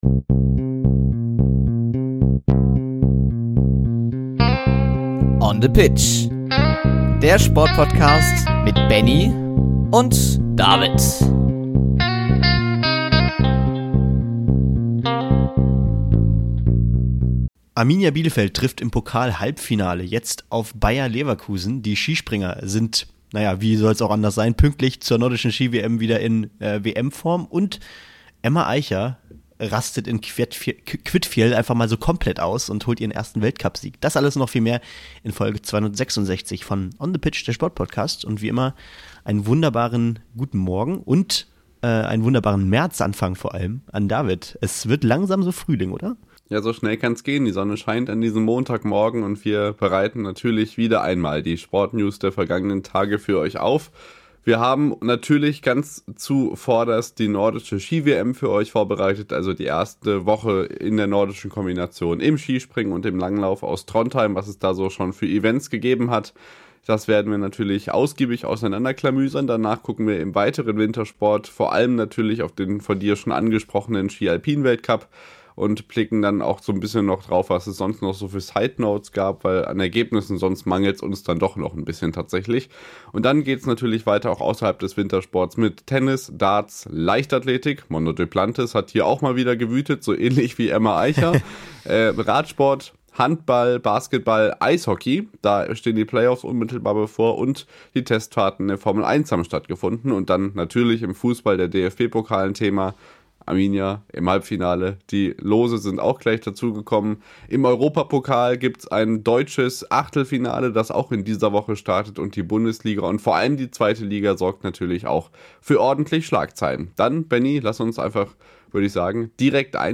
Musikalische Untermalung gibt es in der heutigen Folge gleich zweifach: Der Bürgermeister Trondheims eröffnet die WM mit einem Ständchen und Stabhochsprung-Ass Duplantis veröffentlicht seine erste Single.